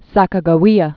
(săkə-gə-wēə, sä-kägä-wēä) or Sac·a·ja·we·a (săkə-jə-wēə) 1787?-1812?